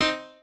piano3_32.ogg